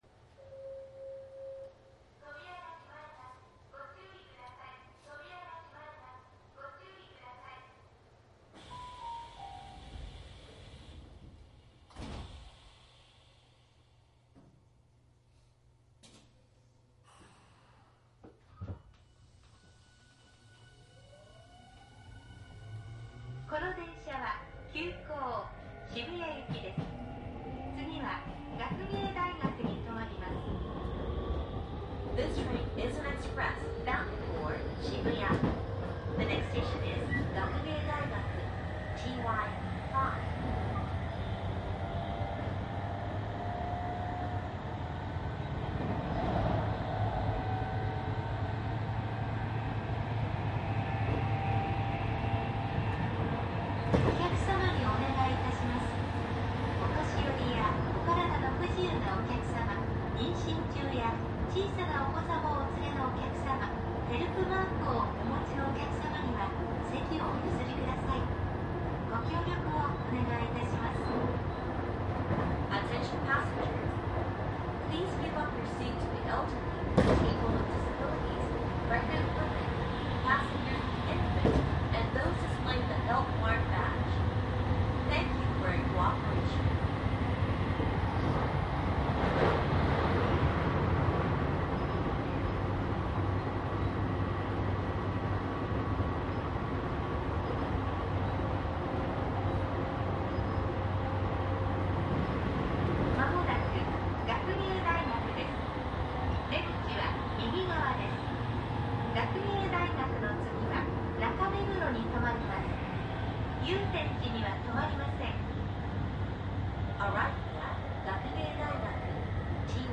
東横線4000番台・相鉄20000系 和光市方面 走行音CD♪♪
マスター音源はデジタル44.1kHz16ビット（マイクＥＣＭ959）で、これを編集ソフトでＣＤに焼いたものです。